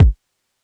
DJP_KICK_ (155).wav